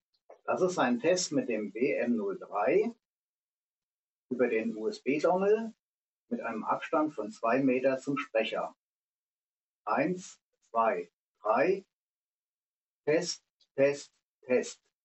Beispiel: USB Dongle Verbindung (gleiches Gerät wie im vorherigen Audiotest)